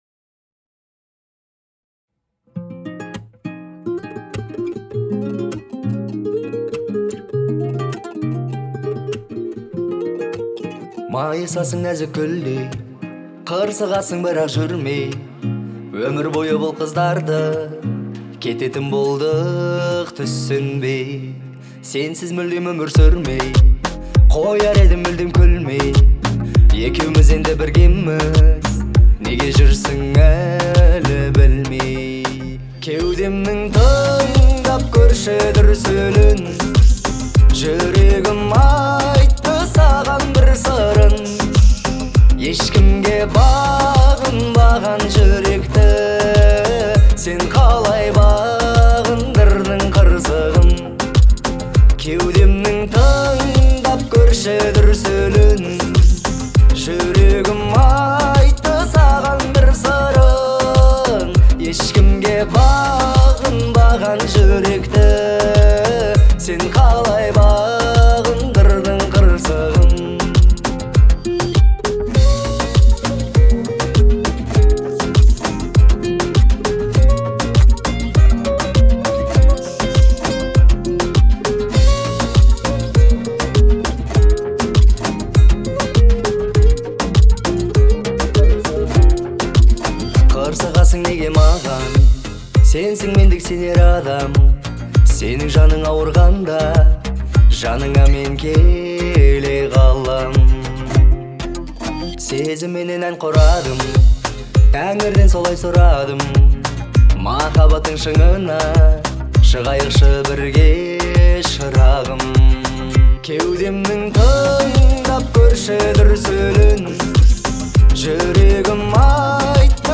это композиция в жанре казахской поп-музыки